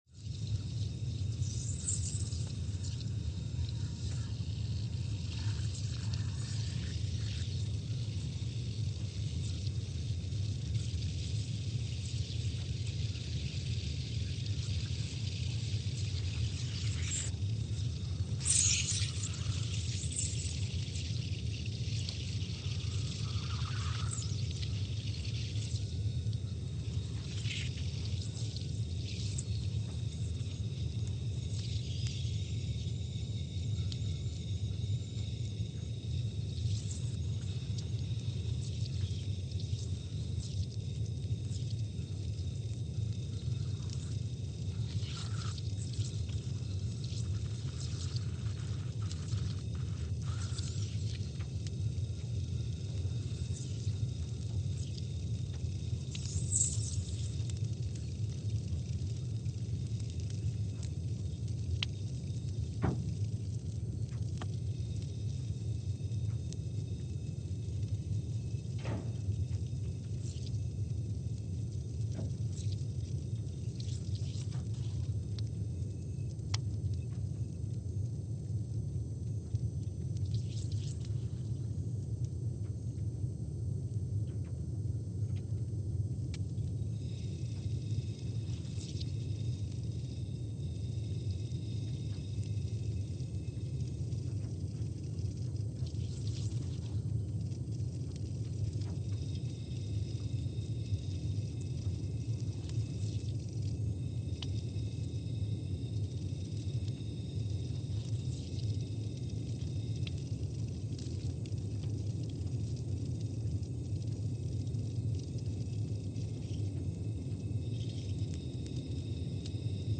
Scott Base, Antarctica (seismic) archived on January 5, 2020
Sensor : CMG3-T
Speedup : ×500 (transposed up about 9 octaves)
Loop duration (audio) : 05:45 (stereo)
Gain correction : 25dB